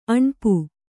♪ aṇpu